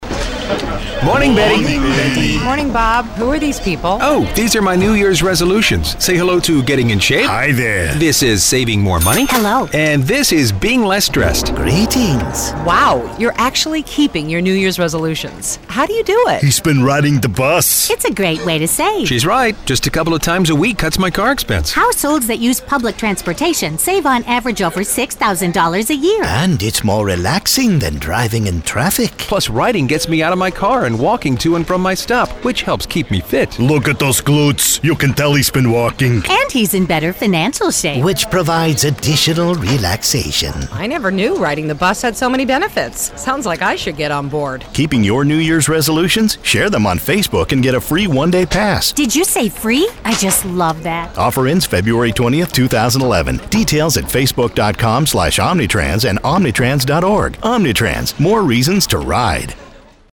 2011 AdWheel Awards First Place Award Electronic Media – Radio, single spot Omnitrans, New Year’s Resolutions.